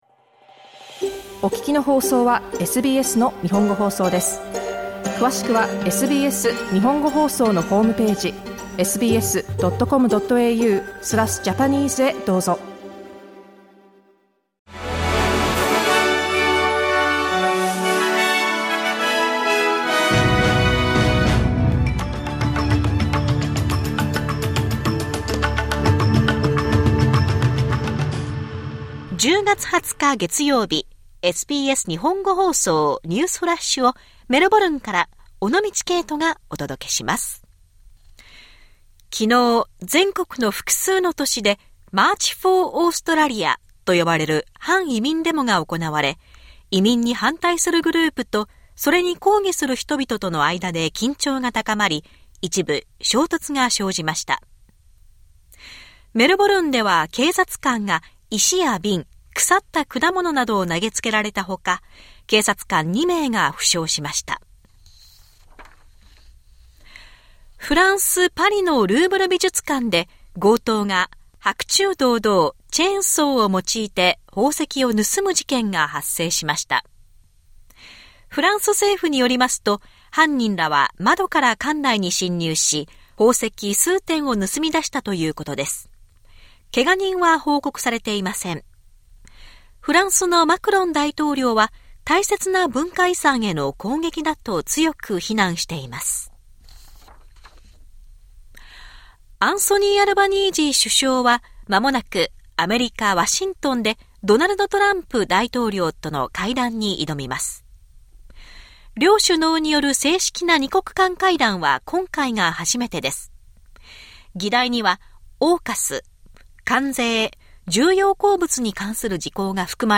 SBS日本語放送ニュースフラッシュ 10月20日 月曜日